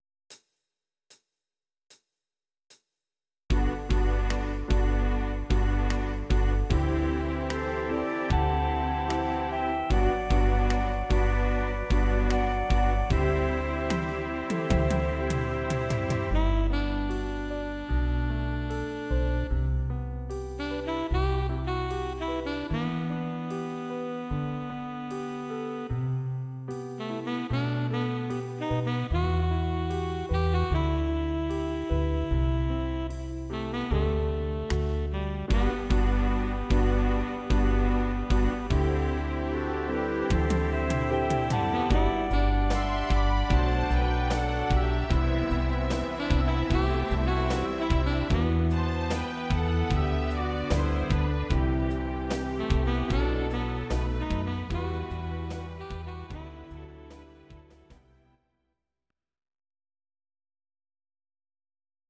Audio Recordings based on Midi-files
Pop, Musical/Film/TV, 1980s